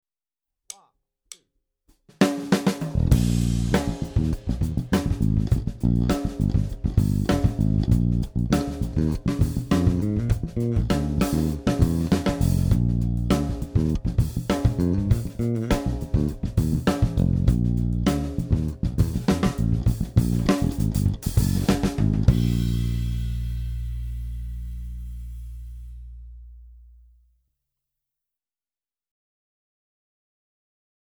音源8:ゴーストノート